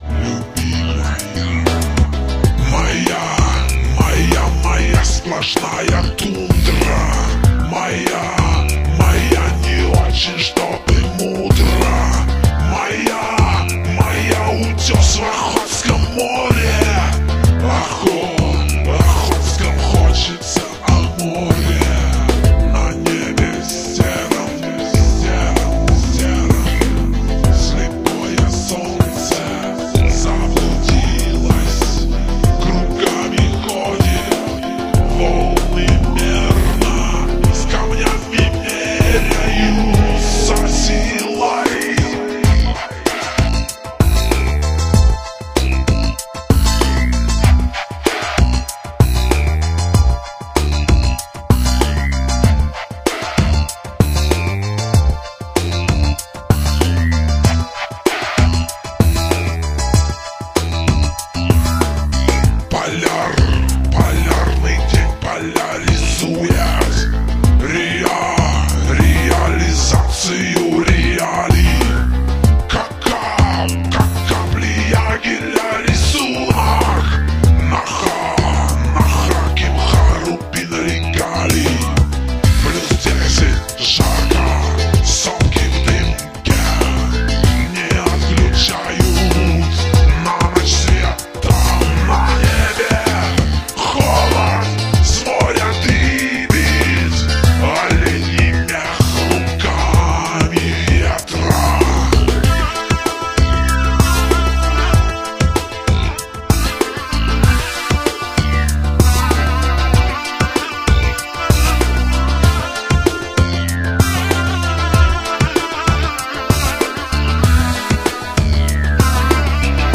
Грустная песня страдающего чукчи.